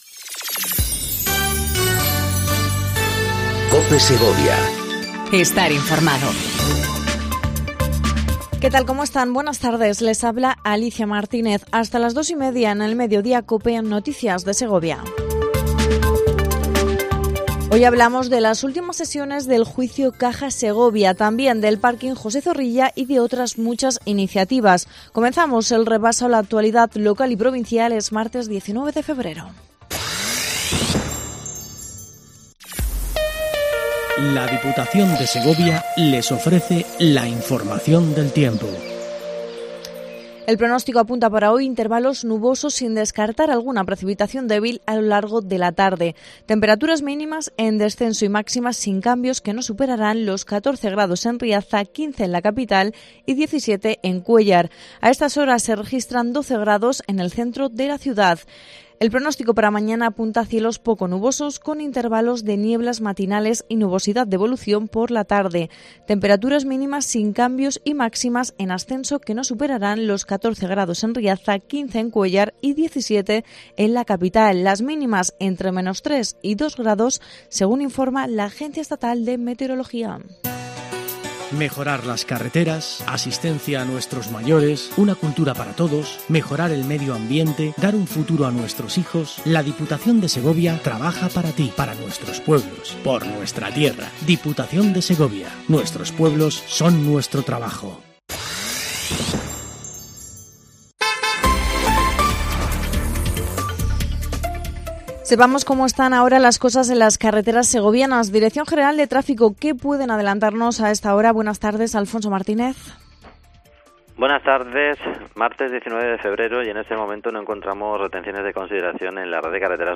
INFORMATIVO DEL MEDIODÍA EN COPE SEGOVIA 14:20 DEL 19/02/19